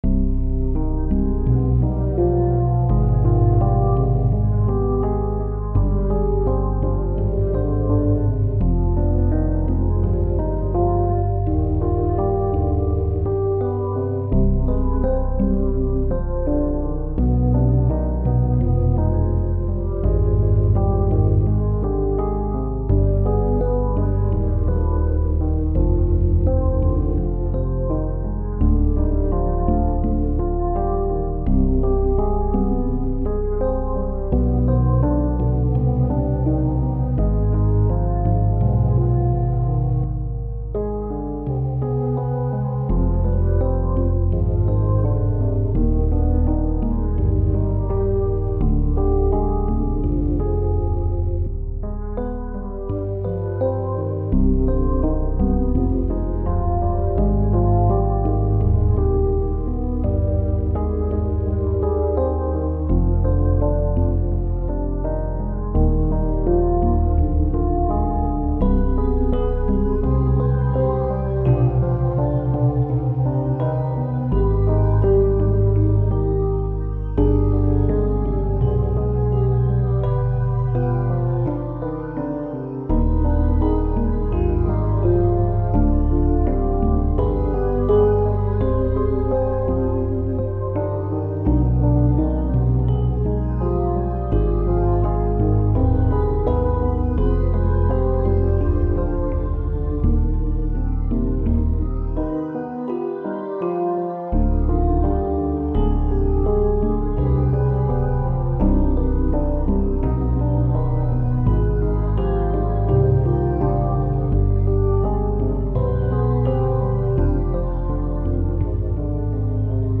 On repart sur une série "Fractale Piano".
Morse (14 - 155 ) Fa (F) Minor Harmon. 84
Plugins : MDA Piano, Organized trio, synth1